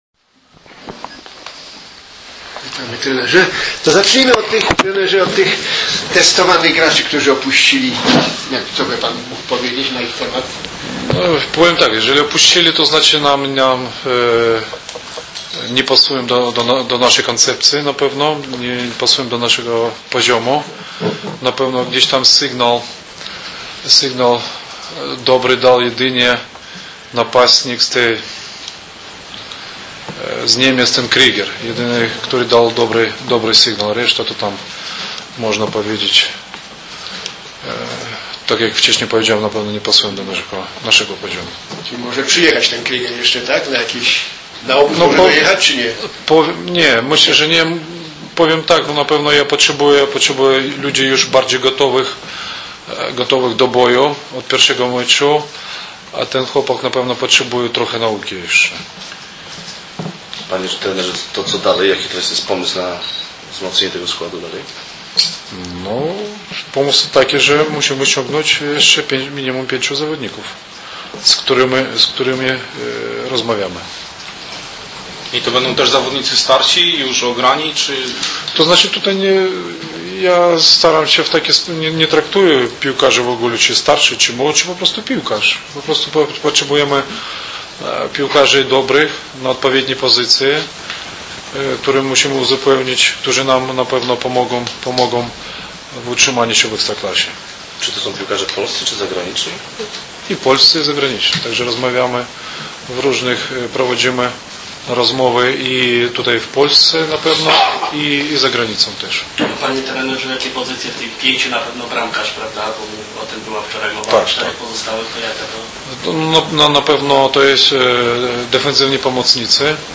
Konferencja prasowa